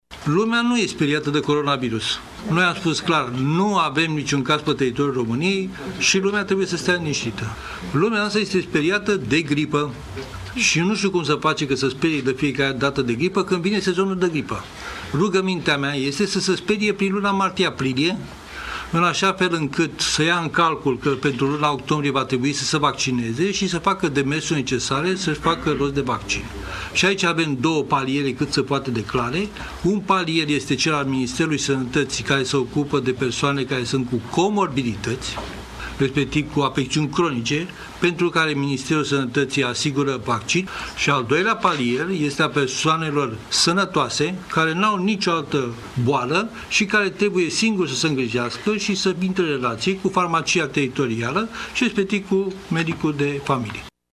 La rândul său, managerul Institutului de Boli Infecțioase Matei Balș, Adrian Streinu Cercel, a spus că riscul ca această afecțiune să ajungă pe teritoriul României este redus: